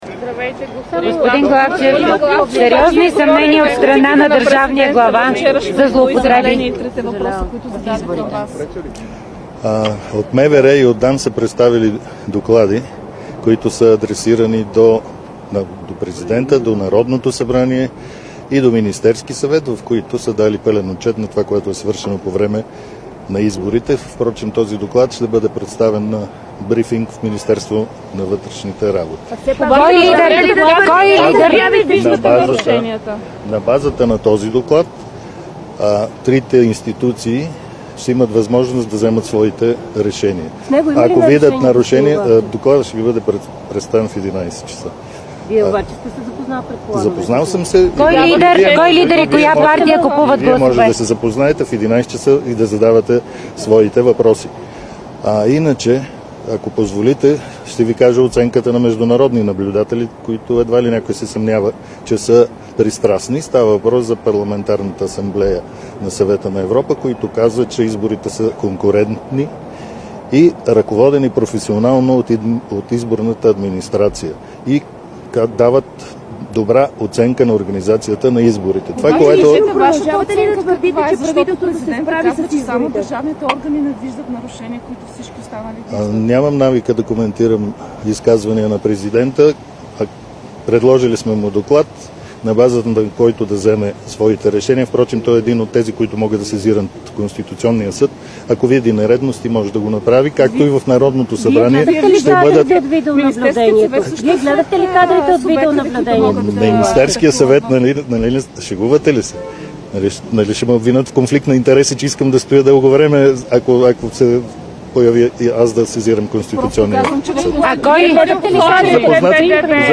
9.40 - Пресконференция на ПП „Величие" на тема „Официална позиция на ПП „Величие" относно фалшифицирането на изборите октомври 2024 г."
Директно от мястото на събитието